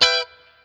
CHORD 2   AG.wav